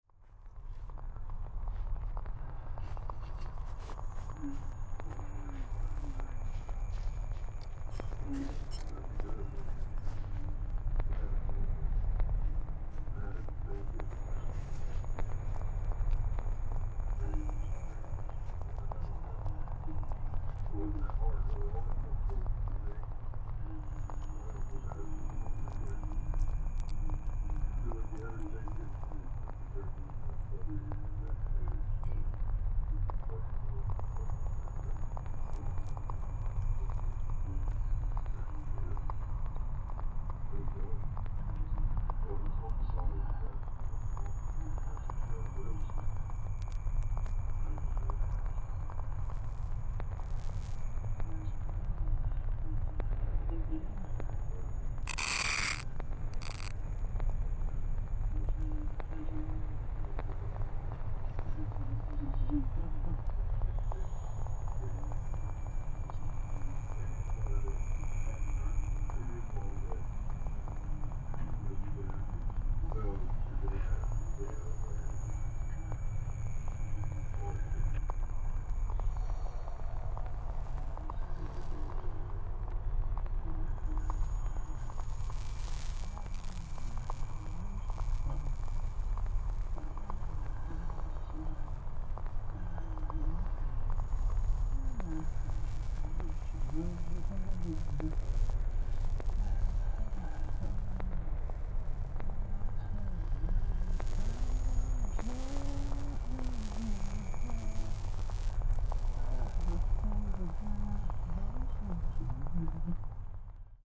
Especially the delay pedals are pushed through the floor.
Things stay very remote and far away.